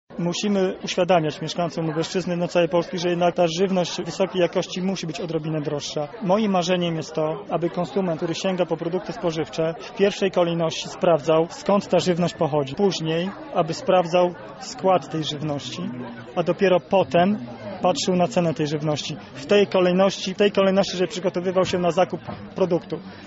O tym w jaki sposób powinniśmy wybierać odpowiednie produkty spożywcze i dlaczego są one droższe mówi Jarosław Stawiarski Marszałek Województwa Lubelskiego.